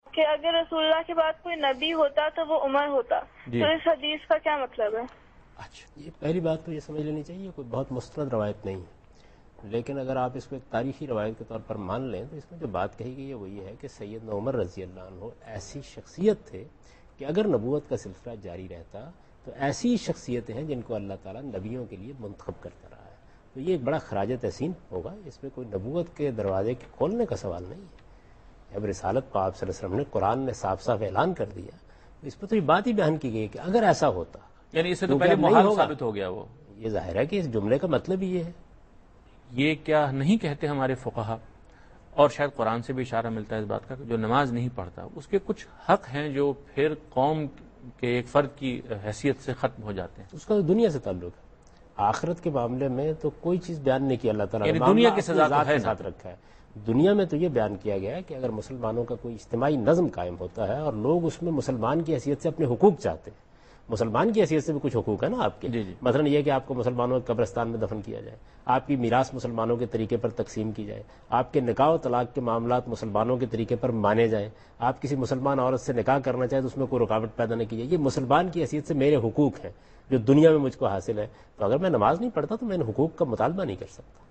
Javed Ahmad Ghamidi answers a question regarding "If anyone after Muhammad (sws) could be a Messenger it would have been 'Umar" in program Deen o Daanish on Dunya News.
جاوید احمد غامدی دنیا نیوز کے پروگرام دین و دانش میں اس حدیث کہ "رسول کے بعد کوئی نبی ہوتا تو وہ عمر ؓ ہوتا" کے متعلق ایک سوال کے جواب دے رہے ہیں۔